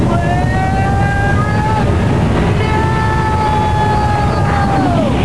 Ryo doing "Flare Up Now"